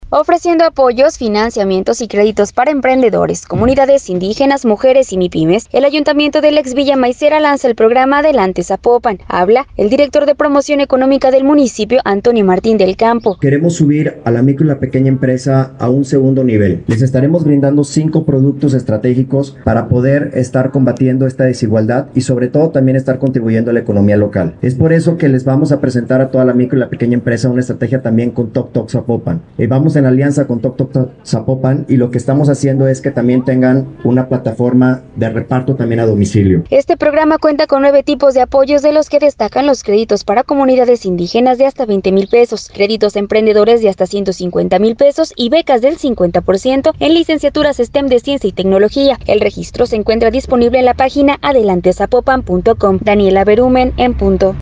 Ofreciendo apoyos, financiamientos, y créditos para emprendedores, comunidades indígenas, mujeres y mipymes el ayuntamiento de la ex villa Maicera, lanza el programa, Adelante Zapopan. Habla el director de Promoción Económica del municipio, Antonio Martín del Campo: